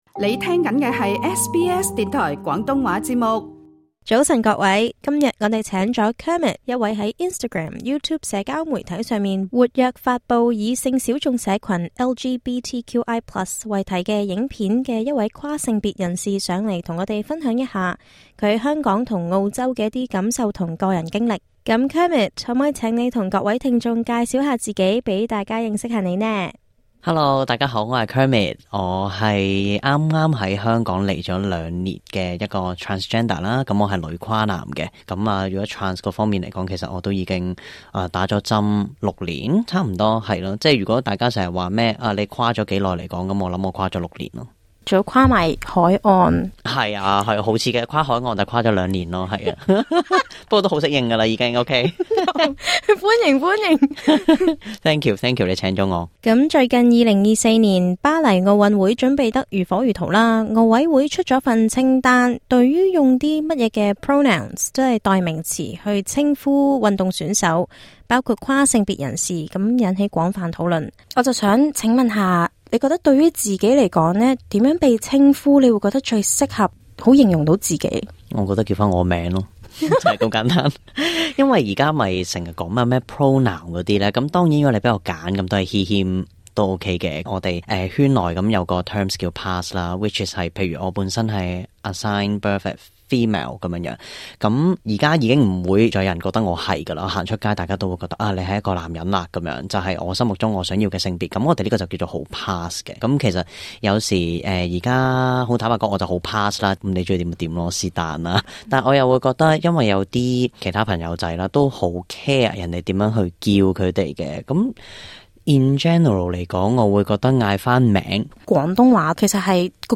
收聽訪問 【入廁所俾人叫變態？